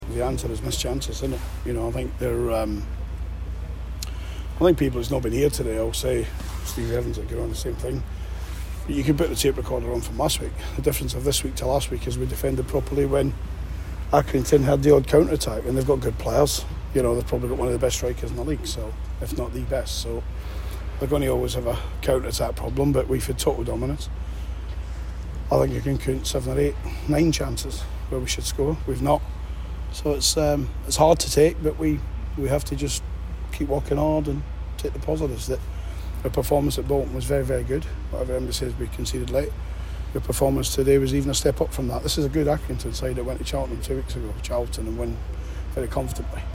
LISTEN: Gillingham manager Steve Evans after Accrington Stanley game ends 0-0